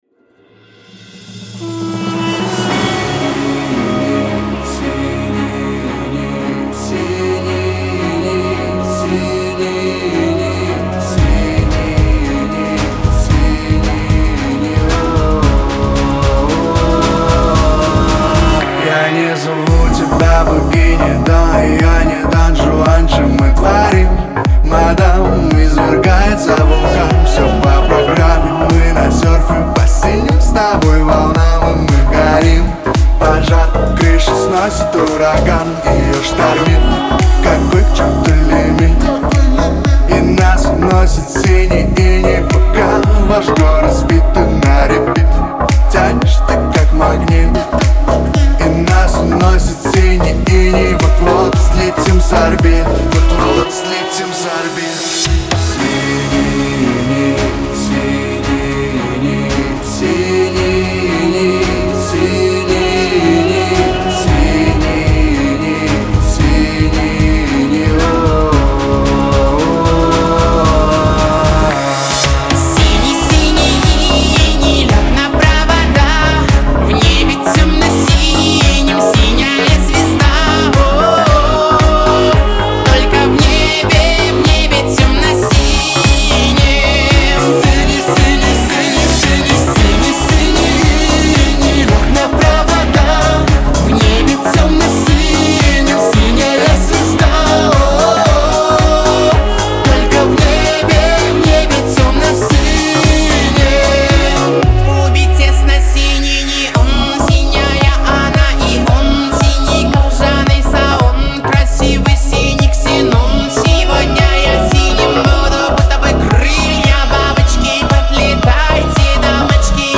Поп